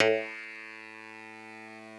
genesis_bass_033.wav